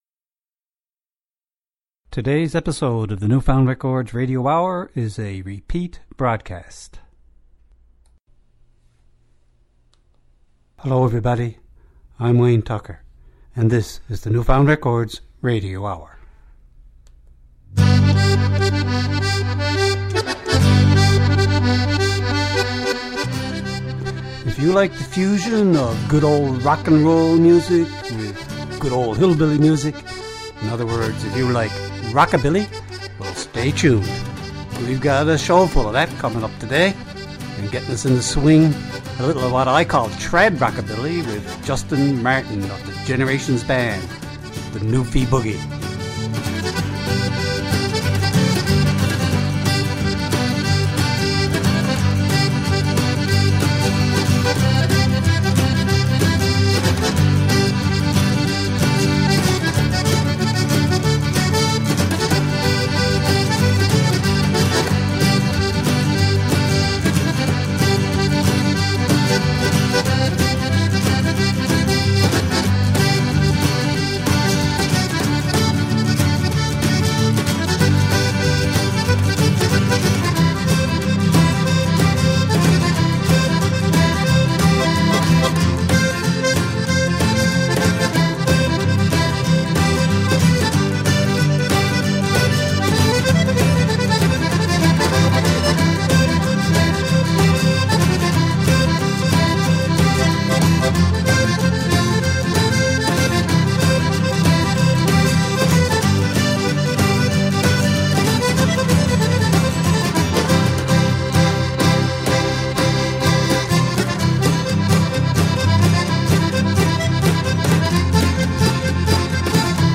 NF Rockabilly
NF_Records_Rockabilly.mp3